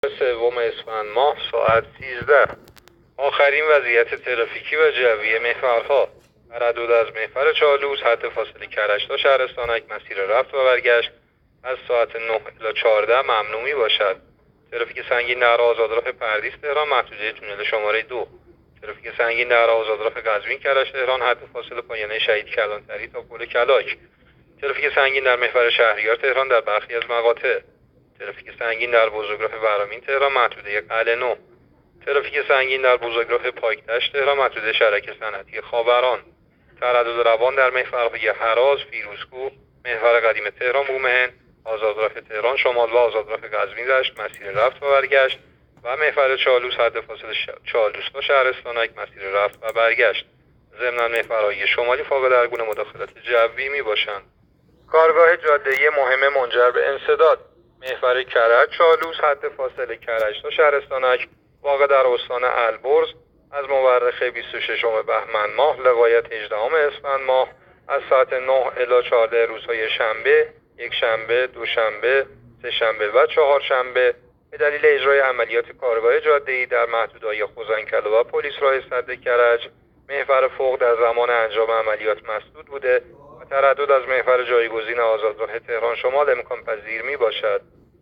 گزارش رادیو اینترنتی از آخرین وضعیت ترافیکی جاده‌ها ساعت ۱۳ سوم اسفند؛